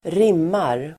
Uttal: [²r'im:ar]